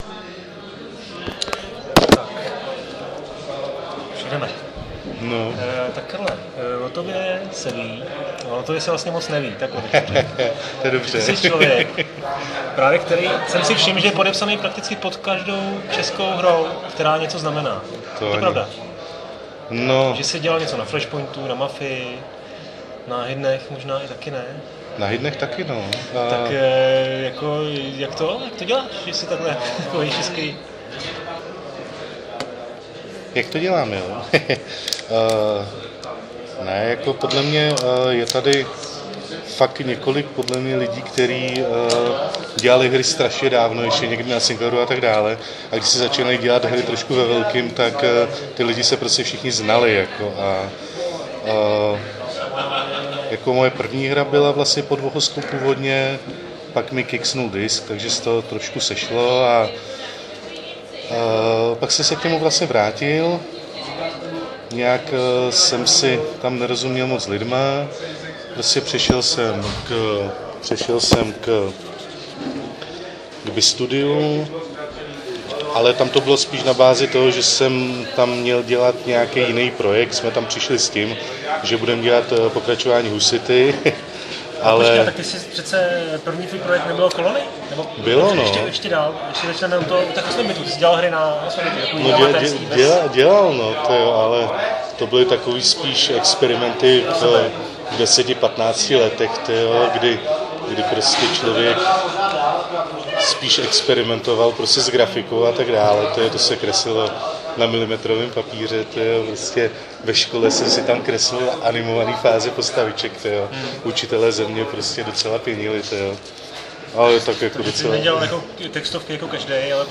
Omluvte místy zhoršenou kvalitu zvuku, najít klidnou hospodu je nemožné.